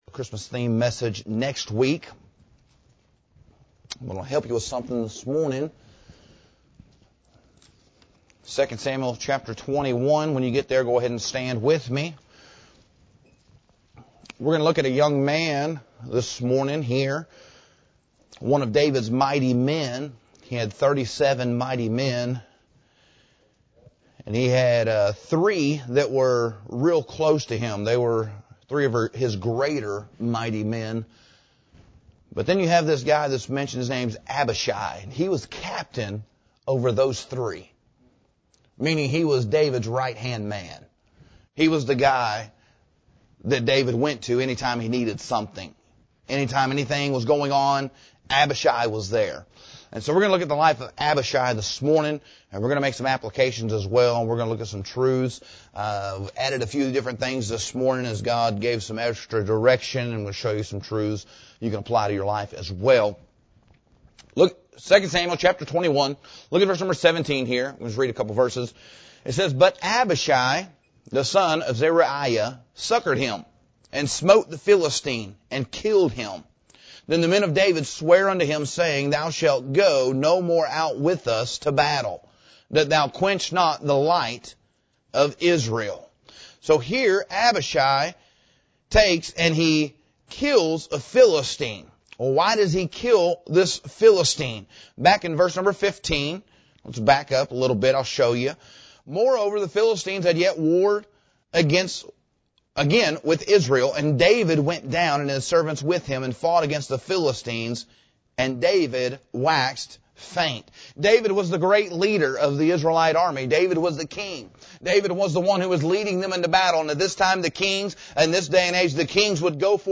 The passage shows David — the giant killer and anointed king — growing weary in battle, and how his loyal fighters, especially Abishai, saw the need, stepped in, and rescued him. This sermon isn’t merely history; it’s a mirror for our local churches and our personal spiritual lives. The message points to four practical character traits we must cultivate so that when God places someone in need before us, we will be ready to act.